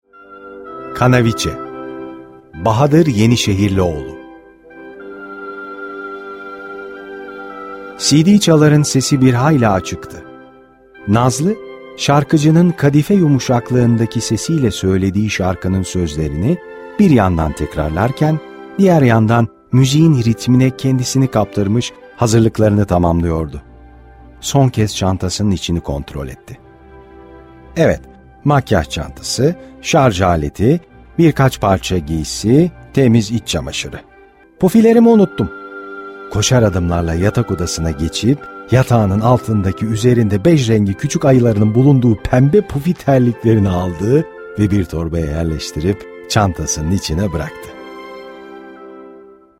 Kitap Seslendirme
Yazılı kitapların, stüdyo ortamında okunarak sesli hale getirilmesi “kitap seslendirme” hizmeti olarak adlandırılır.